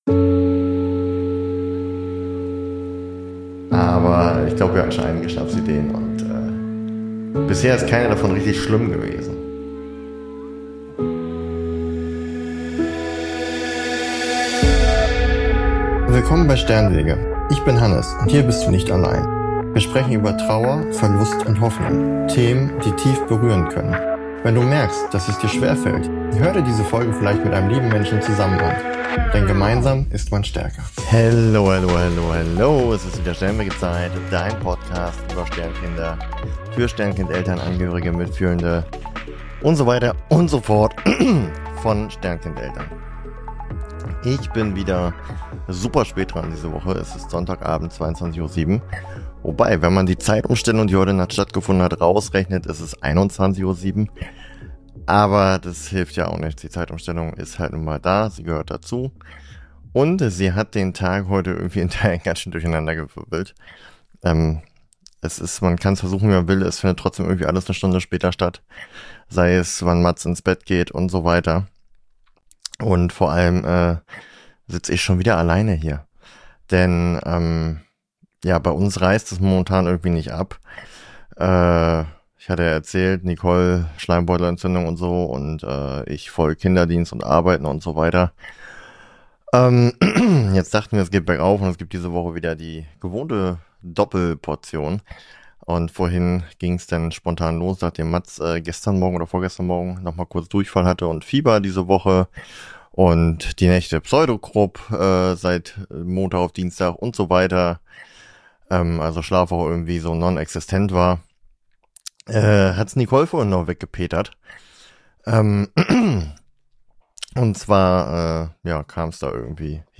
Ich sitze erneut allein am Mikrofon, weil bei uns gerade irgendwie alles gleichzeitig passiert: Zeitumstellung, kranke Familie, wenig Schlaf und ein Alltag, der sich gerade mehr nach Ausnahmezustand als nach Routine anfühlt.